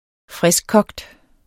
Udtale [ -ˌkʌgd ]